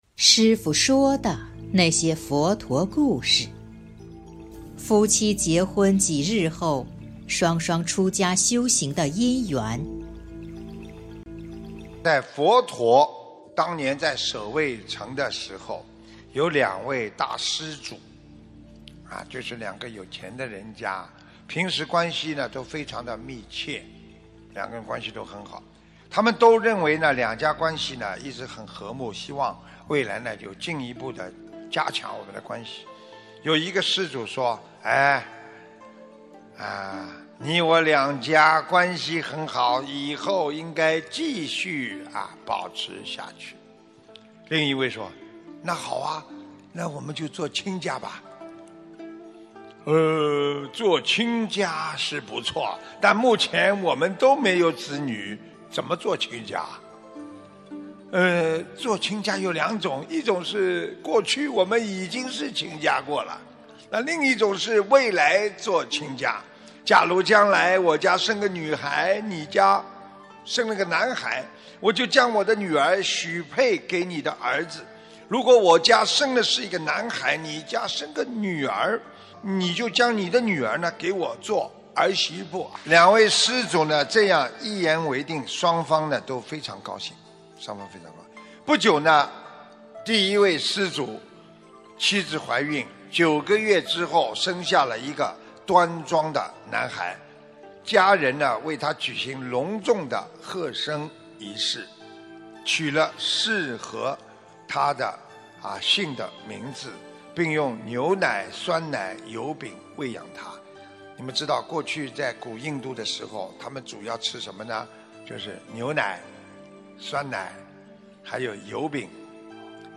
视频：039.《夫妻结婚几日、双双出家修行的因缘》师父说的那些佛陀故事！【师父原声音】 - 师父说佛陀故事 心灵净土